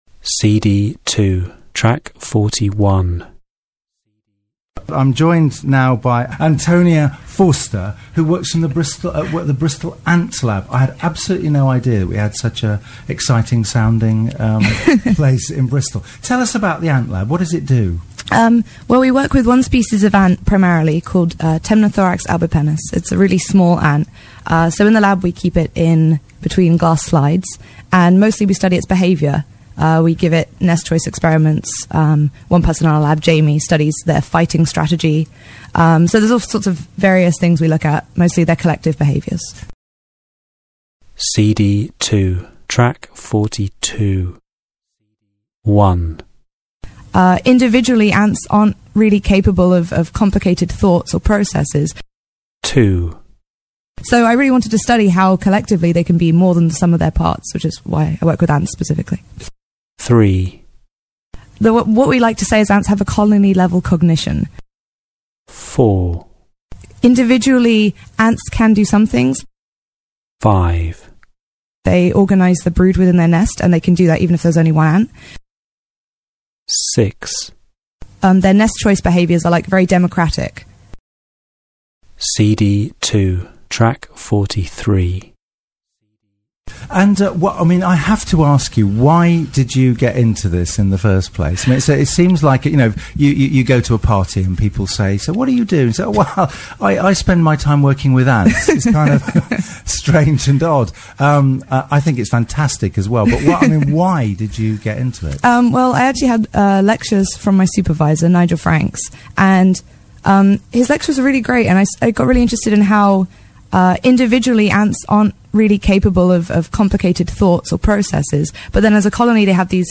This is an authentic listening activity from a radio programme on the subject of ants.